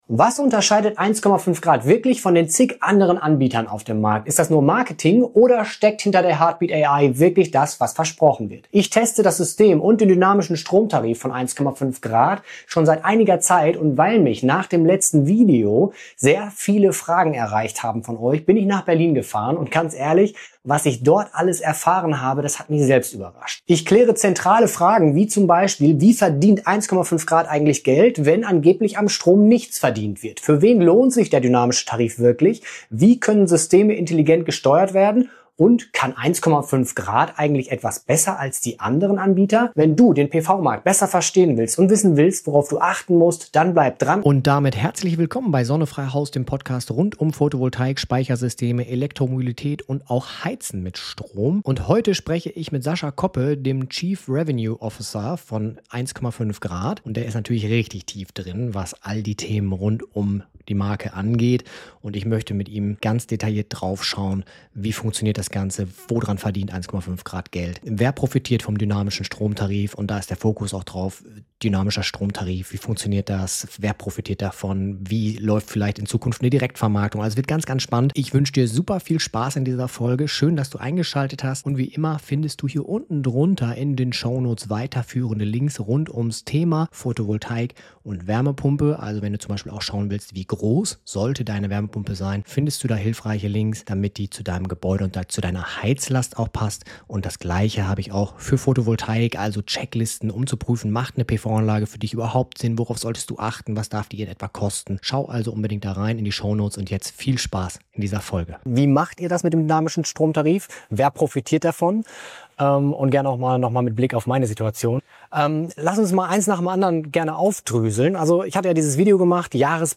Dynamischer Tarif: Abzocke oder echter Vorteil? – Mein Gespräch mit 1KOMMA5° ~ SonneFreiHaus - Photovoltaik, Speicher, eMobilität und Energiemanagement Podcast